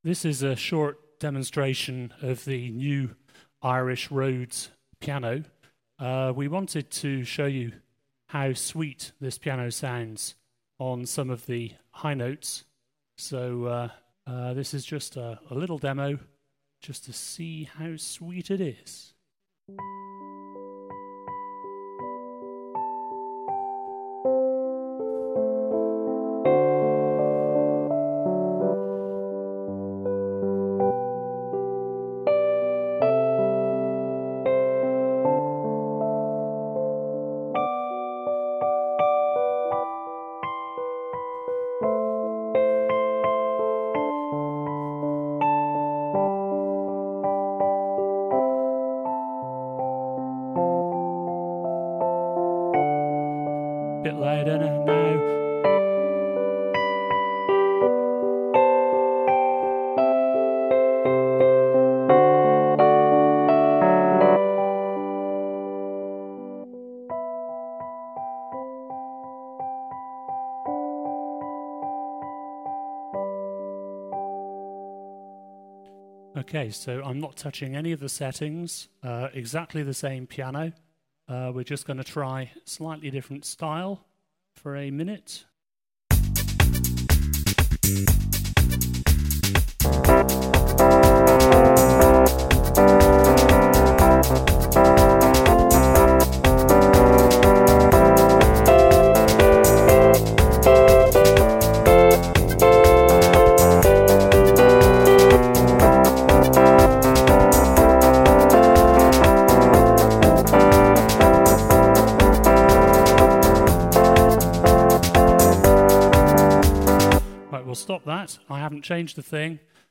at his home.
#3 Here is the Rhodes
ALSO, listen to the drums about 2/3 of the wa through...Korg drums are a lot better than some understand!
09-rhodes-epiano-demo1.mp3